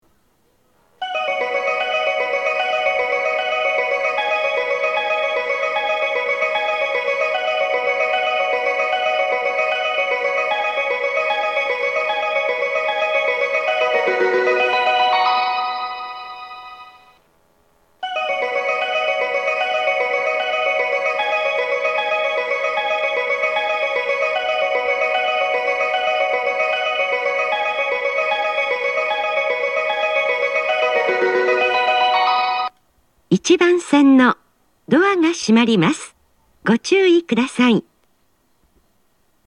発車メロディー
1.9コーラスです!以前の14：22.30発は交換を行う上りの臨時